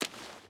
Footsteps / Water / Water Run 5.wav
Water Run 5.wav